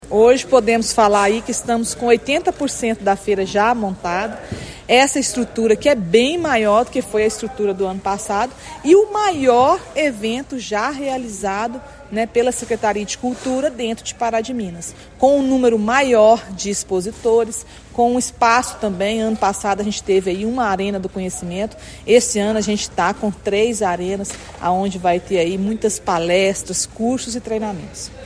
A secretária municipal de Cultura e Comunicação Institucional, Andréia Xavier Paulino, disse ao Portal GRNEWS que a estrutura já está praticamente pronta, com área três vezes maior que a utilizada no ano anterior, possibilitando novas oportunidades aos expositores: